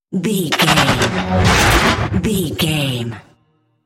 Sci fi gun shot hit whoosh
Sound Effects
heavy
intense
aggressive